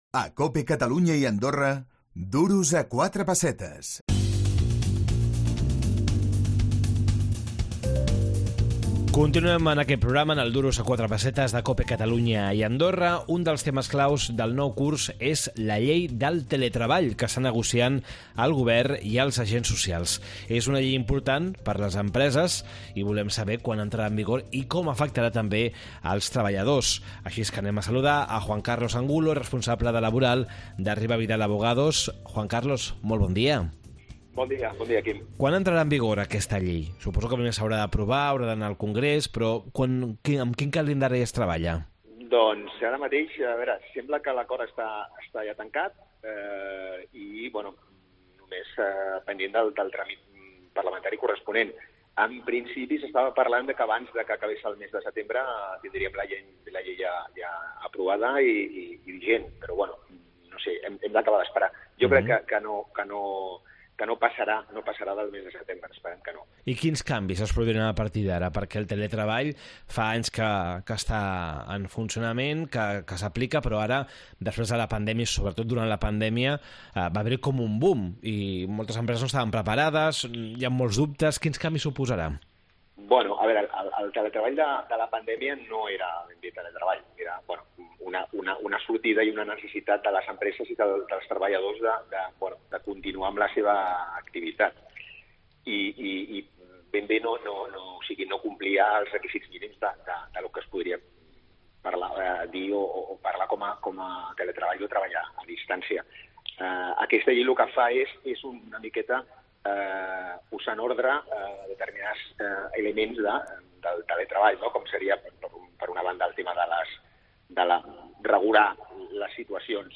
Duros a quatre pessetes, el programa d’economia de COPE Catalunya i Andorra.